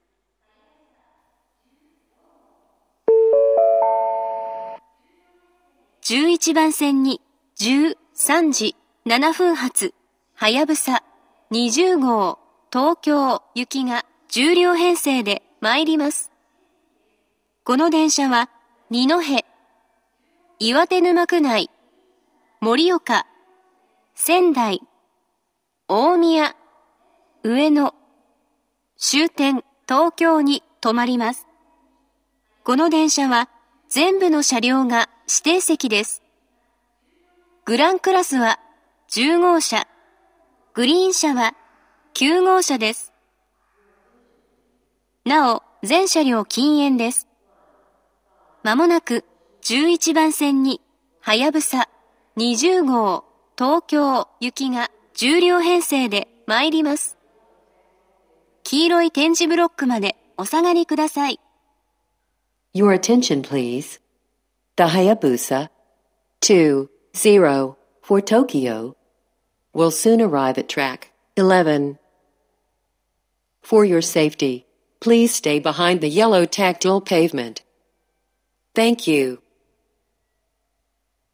１１番線接近放送